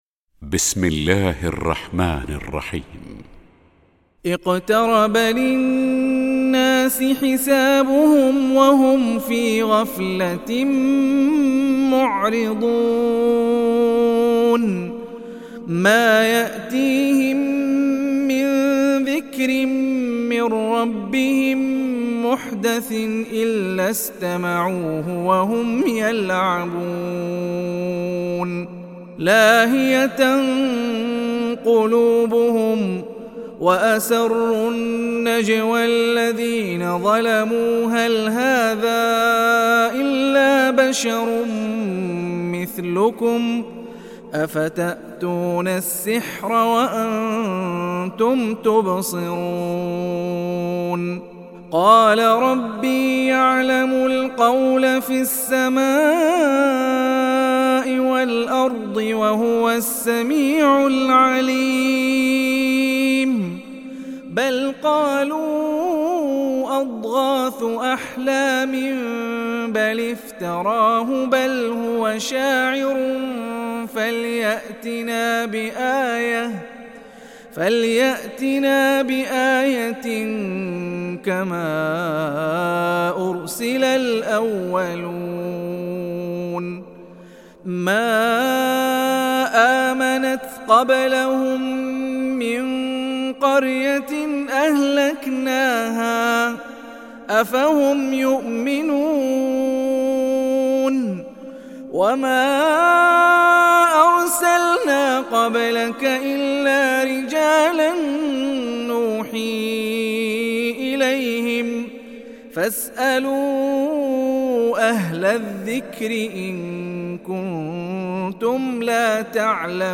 تحميل سورة الأنبياء mp3 بصوت هاني الرفاعي برواية حفص عن عاصم, تحميل استماع القرآن الكريم على الجوال mp3 كاملا بروابط مباشرة وسريعة